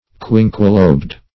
Quinquelobed \Quin"que*lobed`\, a.
quinquelobed.mp3